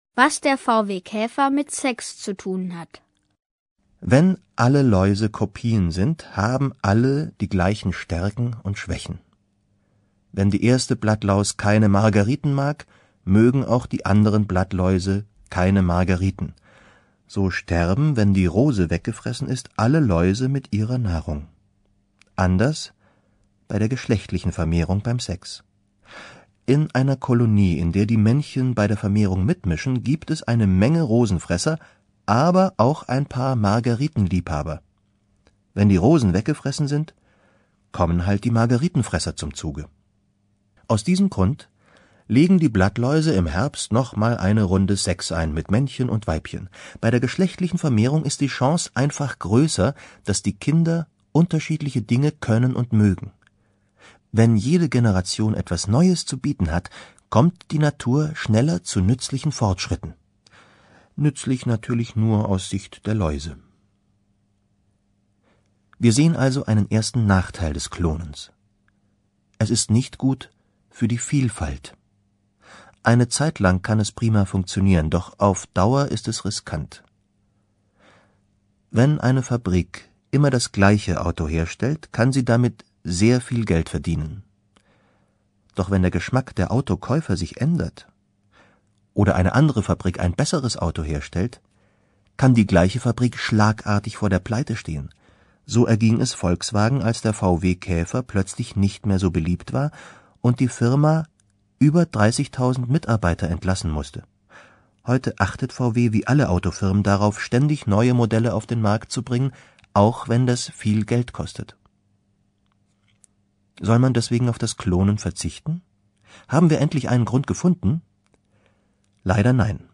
Hörbuch: Die Kinder-Uni.